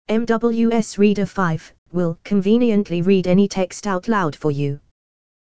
Sprecher für das Vorleseprogramm MWS Reader
Englischsprachige Stimmen (Britisches Englisch)
Microsoft Speech Platform – Runtime Language (Version 11)